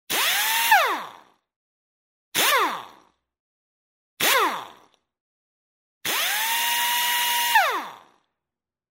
Звук металлического диска от машины катится и падает